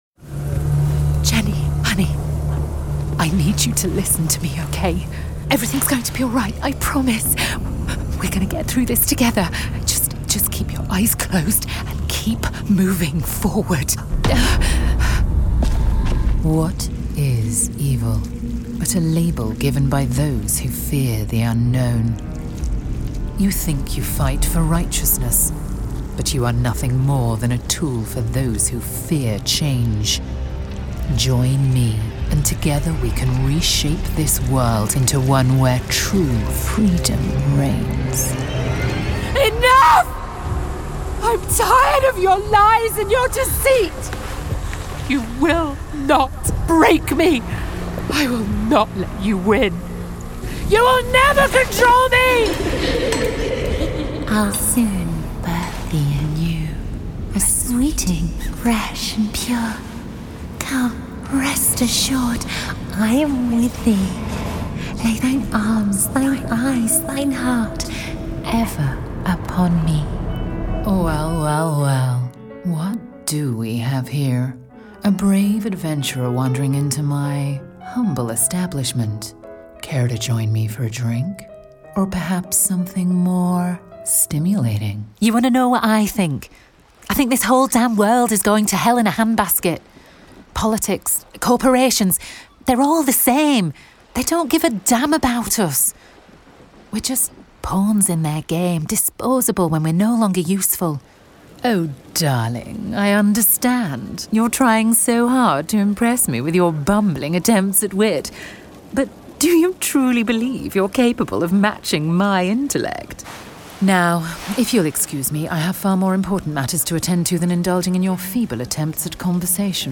Inglés (Británico)
Natural, Amable, Cálida, Empresarial, Versátil